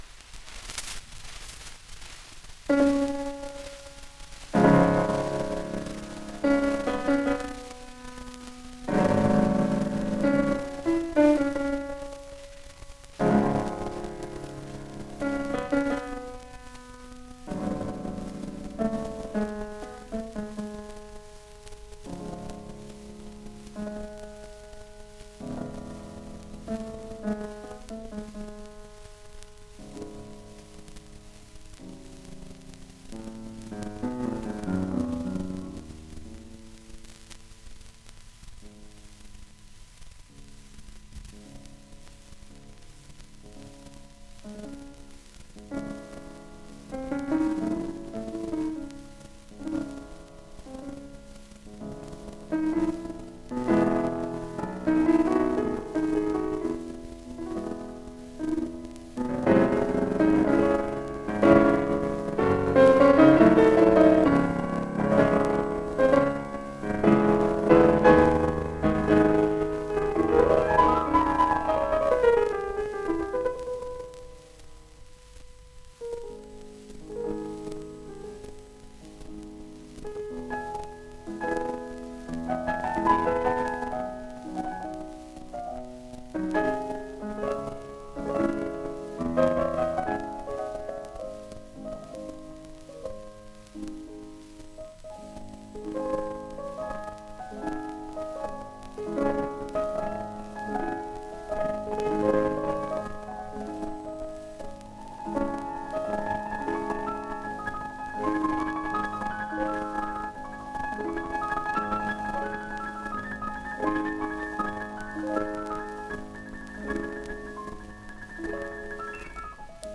1 disco : 78 rpm
klavier Editorial